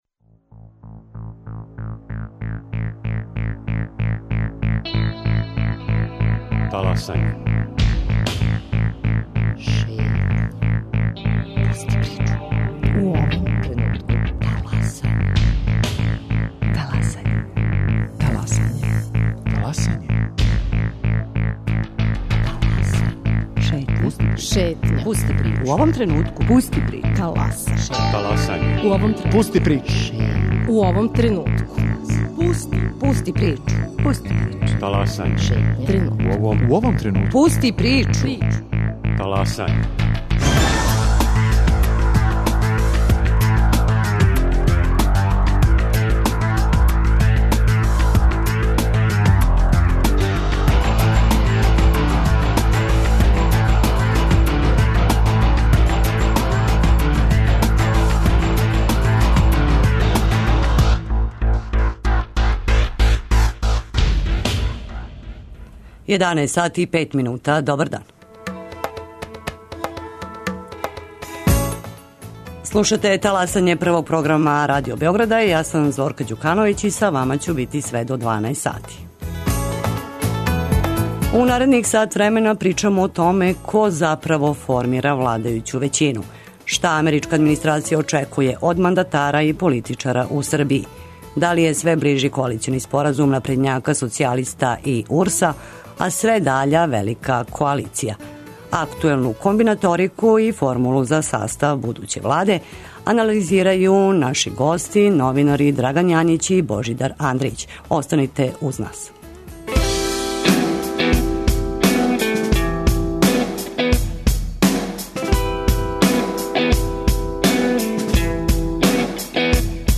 Актуелну комбинаторику и формулу за састав будуће Владе анализирају гости емисије новинари